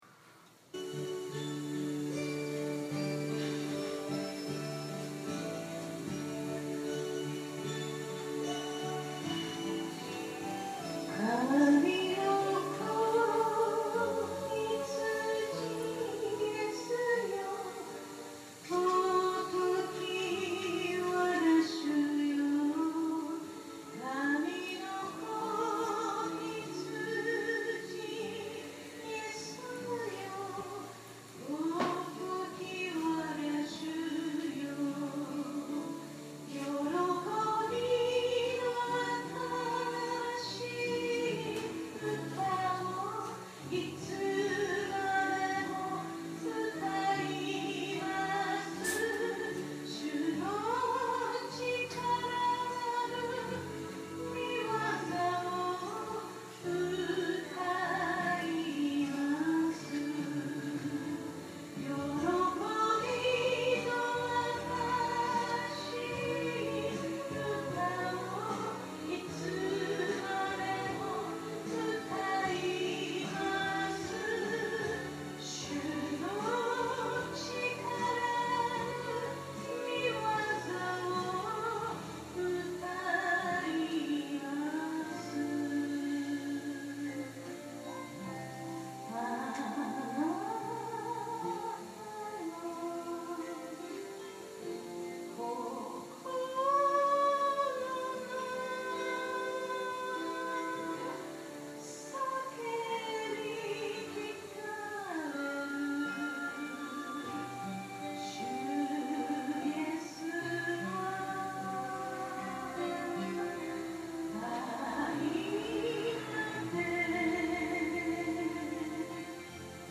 2014年6月15日（日）礼拝説教 『私は行って、初めの夫に戻ろう』